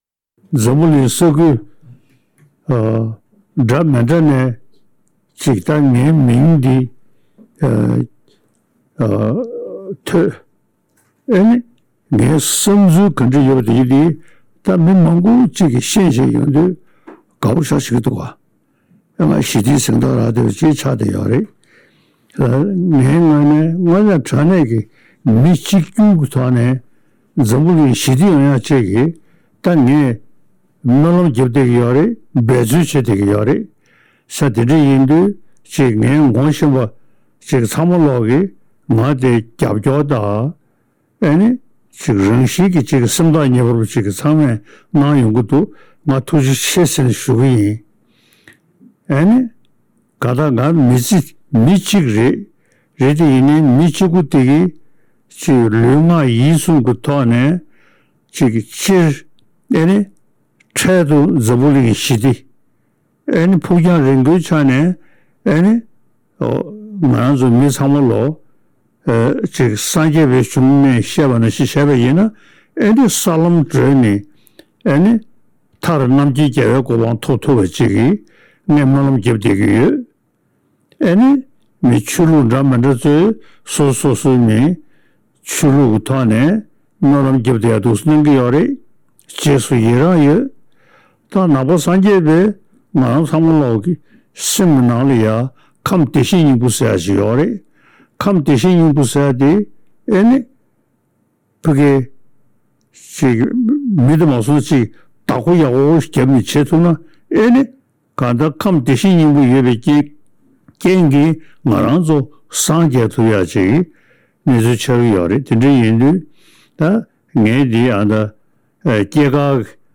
གནས་འདྲི་ཞུས་ནས་གནས་ཚུལ་ཕྱོགས་བསྒྲིགས་ཞུས་པ་ཞིག་གསན་རོགས་གནང